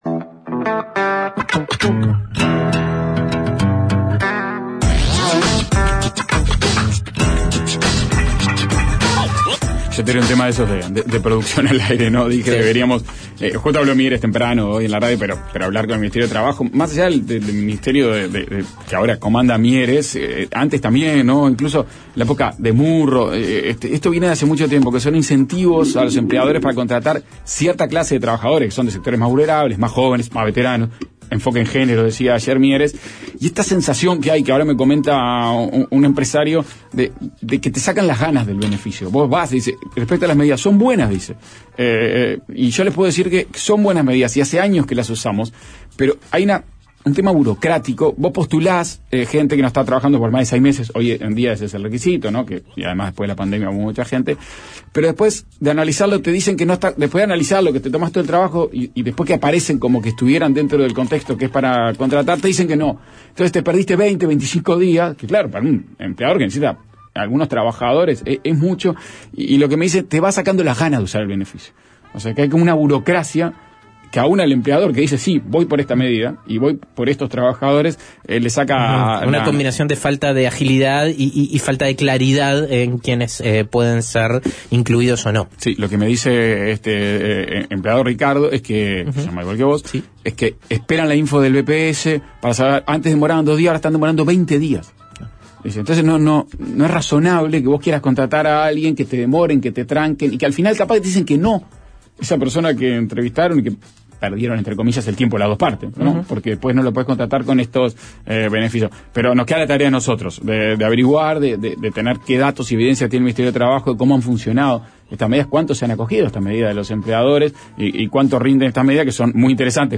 Entrevista con el comisionado parlamentario para el Sistema Carcelario, Juan Miguel Petit.